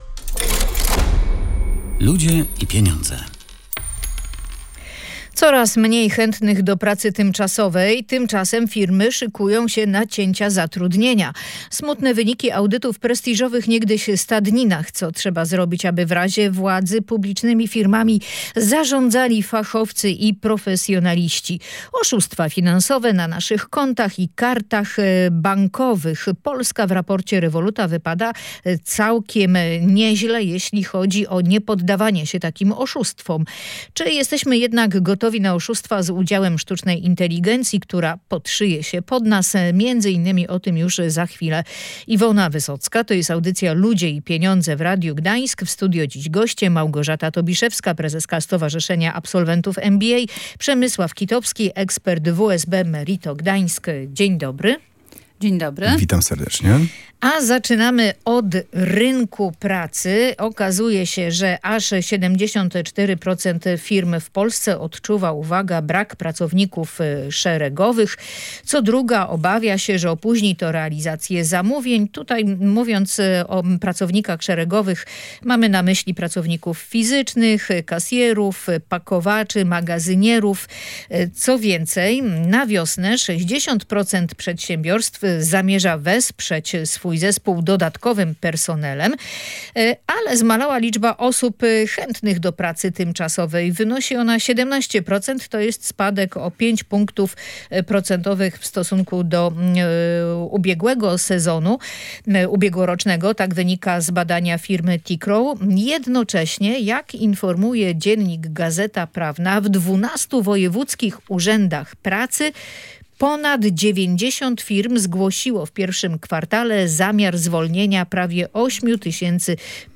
Osoby zarządzające publicznymi firmami powinny być rozliczane ze swojej pracy, podobnie jak szefowie firm prywatnych – uważają goście audycji Ludzie i Pieniądze. Odnieśli się do raportu z audytu w sprawie niegdyś najlepszych na świecie hodowli koni arabskich w Janowie Podlaskim i Michałowie.